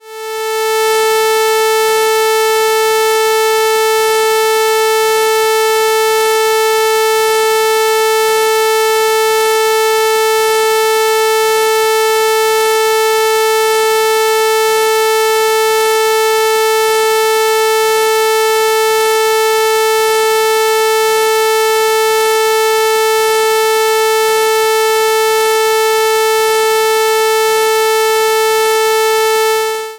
锯齿形440赫兹
描述：使用Audacity生成锯齿波形440Hz 5s。
标签： 锯齿 电子 440Hz的
声道立体声